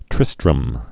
(trĭstrəm)